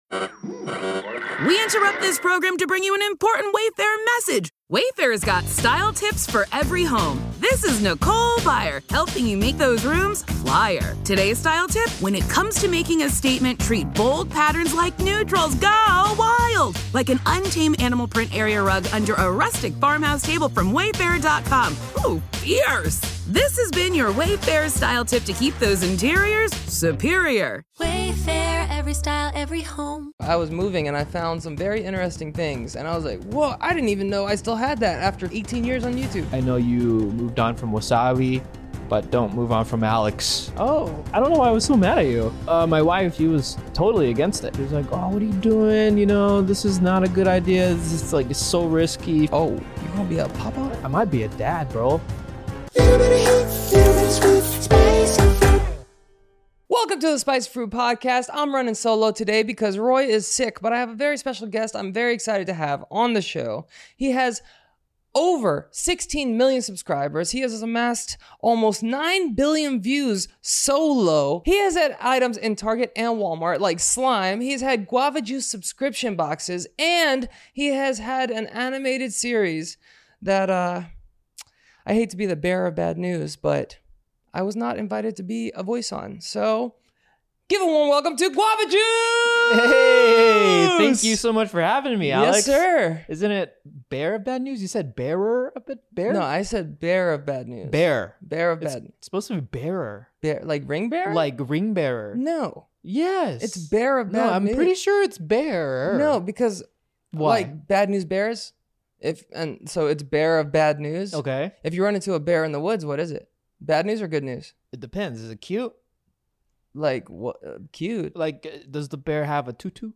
We had a fun conversation about the Guava Juice Journey, and how he got over 9 Billion Views.